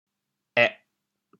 êh4.mp3